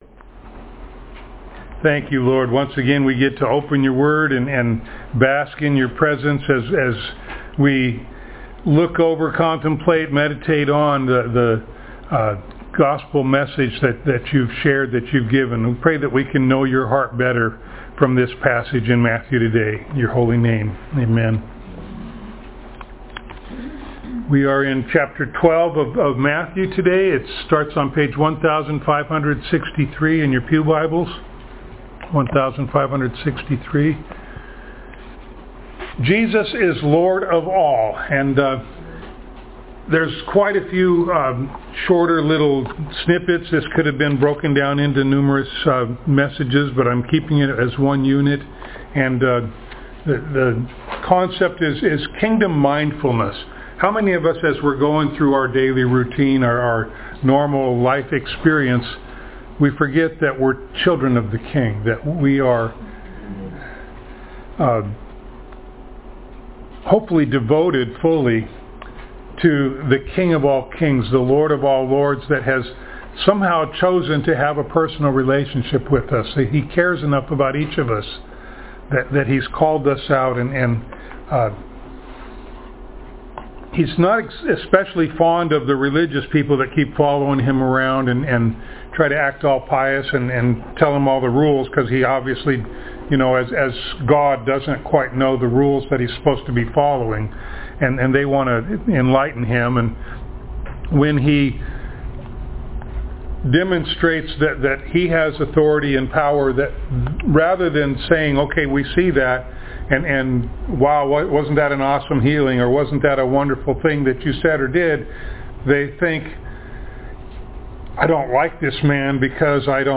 Passage: Matthew 12:1-50 Service Type: Sunday Morning